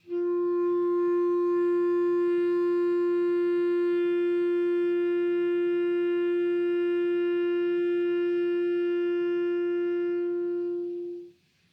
DCClar_susLong_F3_v2_rr1_sum.wav